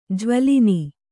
♪ jvalini